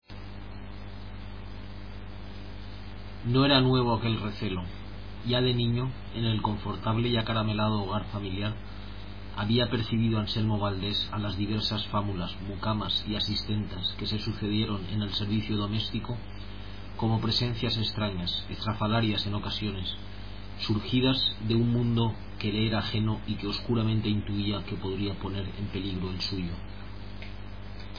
Para escuchar la voz del autor pulsar